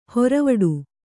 ♪ horavaḍu